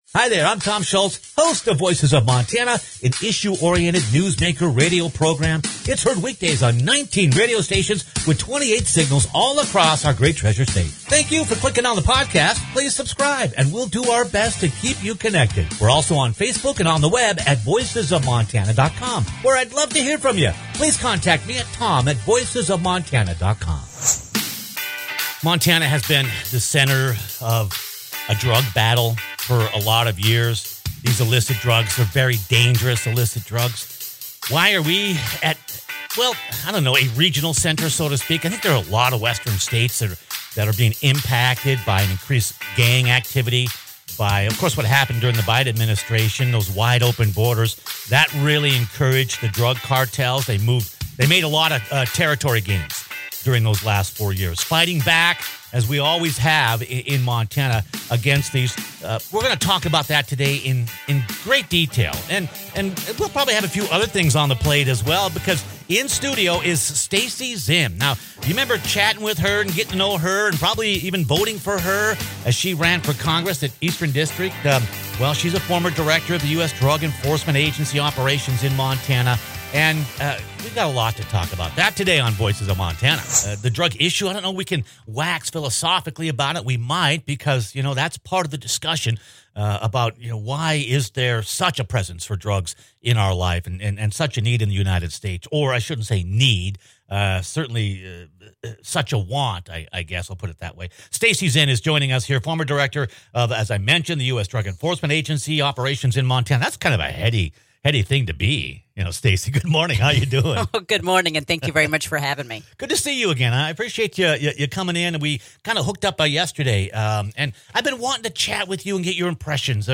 Want to know some real truths about how gangs and cartels are operating in Montana -- and what you can do about it? Click on the podcast for a conversation with someone whose been in the trenches for more than two decades.